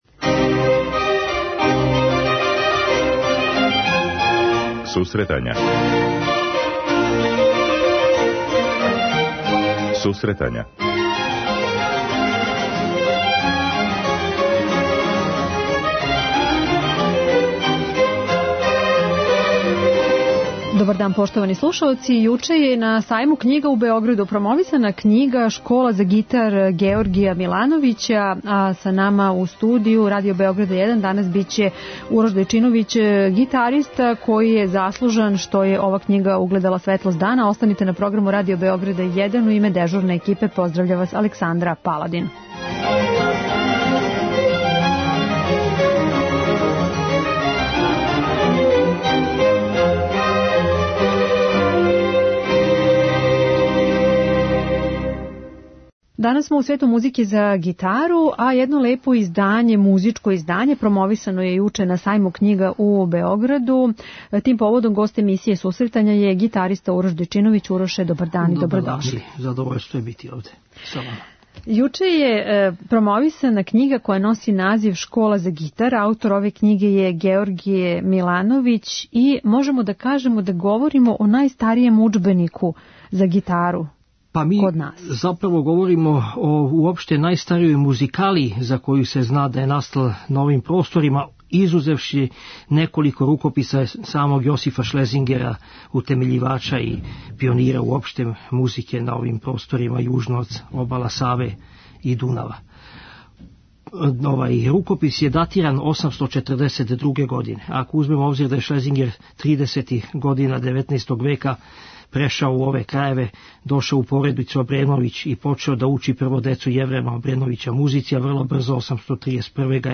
Овај уметник гост је данашње емисије, посвећене гитаристичкој педагогији и едукативној литератури.